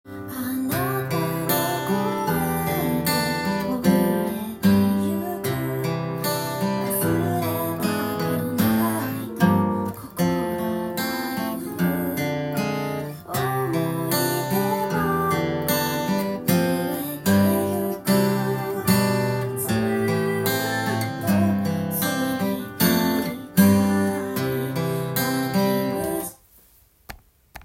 音源にあわせて譜面通り弾いてみました
殆どピックで1本ずつ弾くアルペジオで弾いていきますが
たまに４分音符のコードが入ってきますので